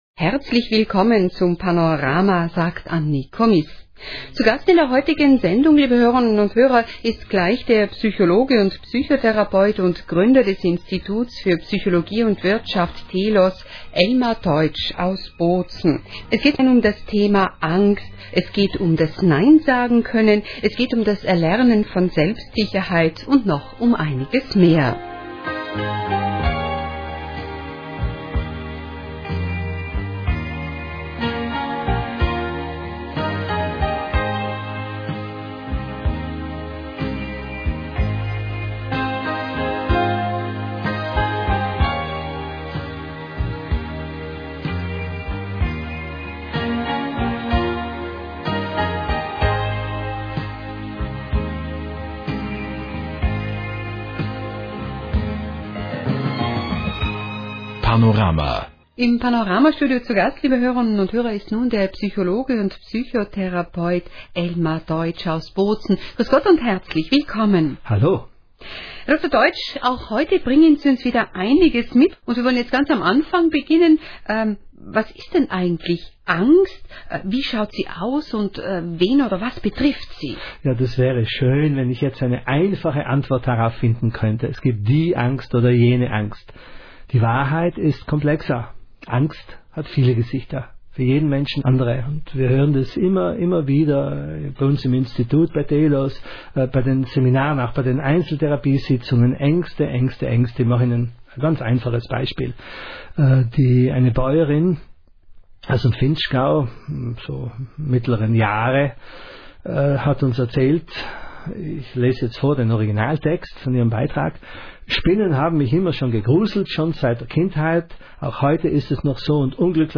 Hören Sie hier kostenlos Auszüge aus der Sendung: